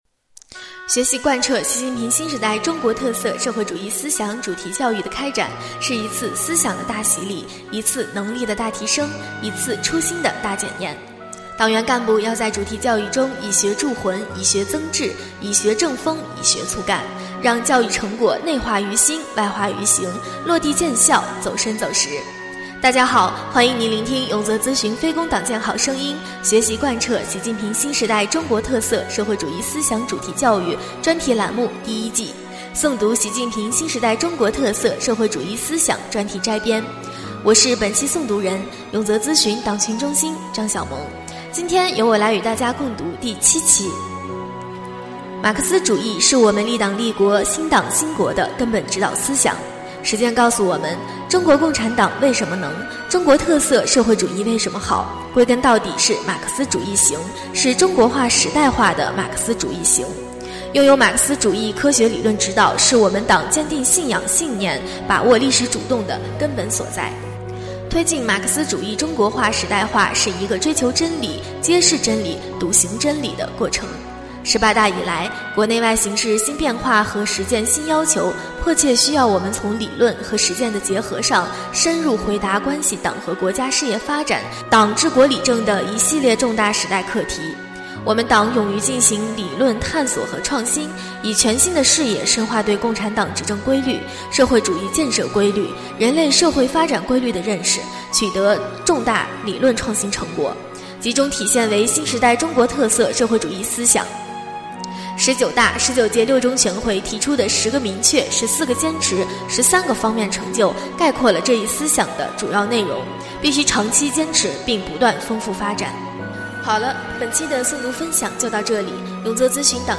【诵读】《习近平新时代中国特色社会主义思想专题摘编》第7期-永泽党建